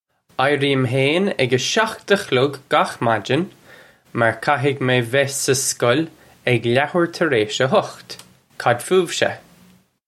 Pronunciation for how to say
Eye-ree-im hayn ig uh shokht uh khlug gakh madgin mar ka-hig may veh sug skol ig lahoor tur aysh uh hokht. Kad foo-iv-shuh?
This is an approximate phonetic pronunciation of the phrase.